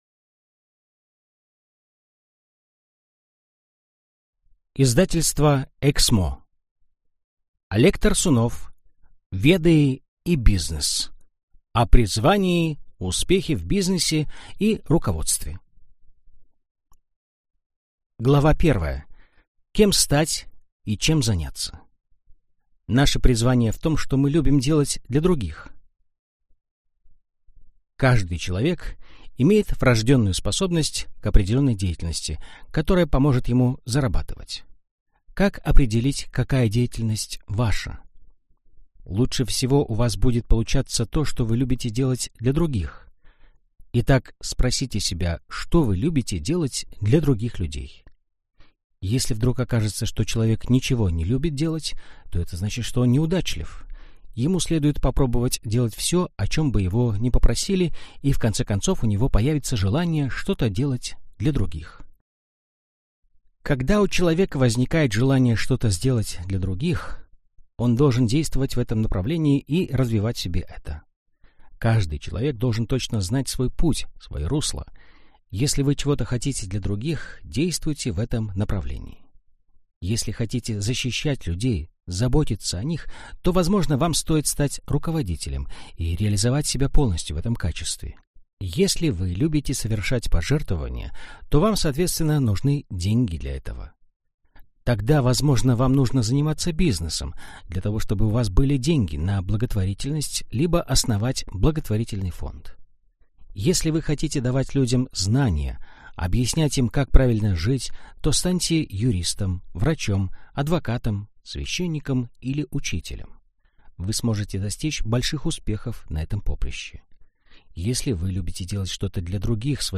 Аудиокнига Веды и бизнес. О призвании, успехе в бизнесе и руководстве | Библиотека аудиокниг